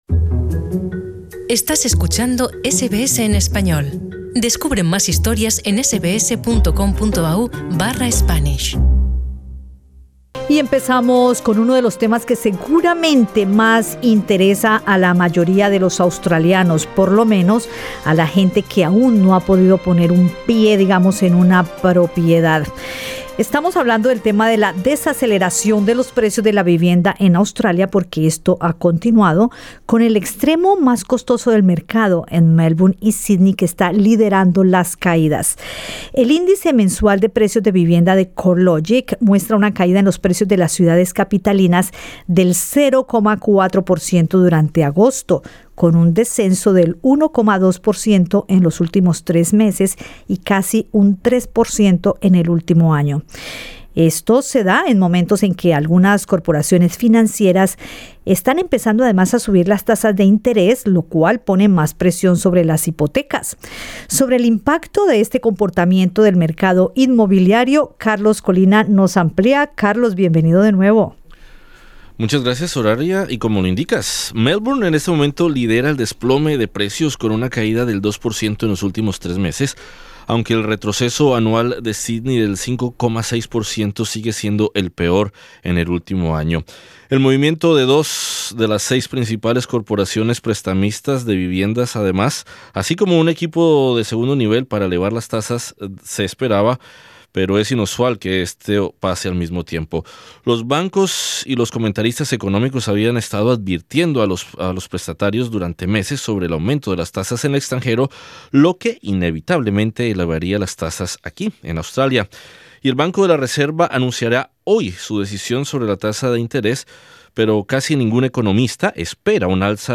Para analizar el impacto de estas medidas conversamos con el economista